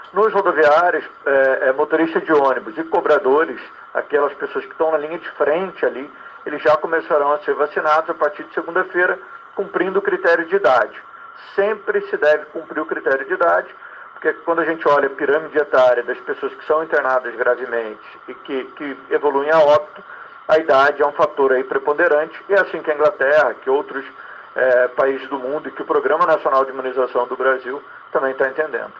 A informação foi divulgada em entrevista coletiva da equipe de Saúde da prefeitura, realizada na manhã desta sexta-feira, 23 de abril de 2021.
O secretário Municipal de Saúde do Rio de Janeiro, Daniel Soranz, disse que o principal critério, mesmo entre os trabalhadores, será a idade.